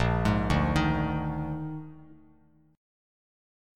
Bb11 chord